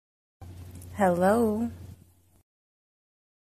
Female Saying Hello sound effect
Thể loại: Âm thanh meme Việt Nam
Description: “Female Saying Hello sound effect” là một hiệu ứng âm thanh dạng meme ngắn gọn, ghi lại tiếng một cô gái nói “Hello” với giọng nữ nhẹ nhàng và thân thiện.
female-saying-hello-sound-effect-www_tiengdong_com.mp3